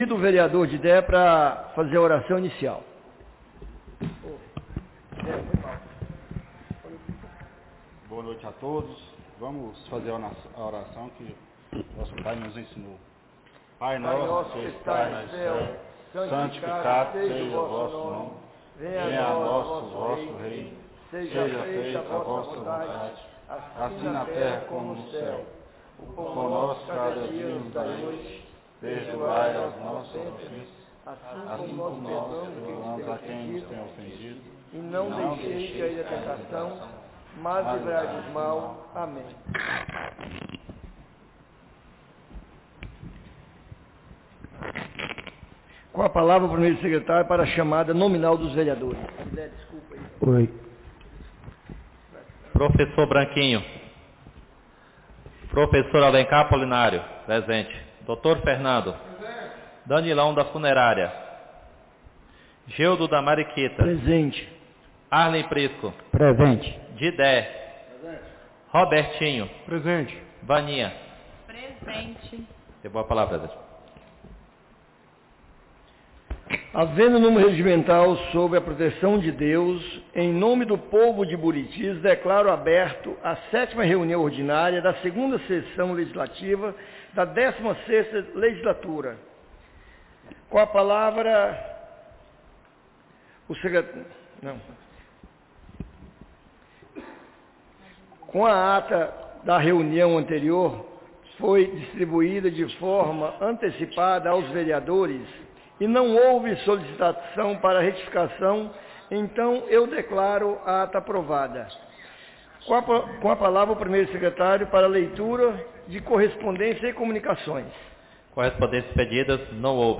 7ª Reunião Ordinária da 2ª Sessão Legislativa da 16ª Legislatura - 16-03-26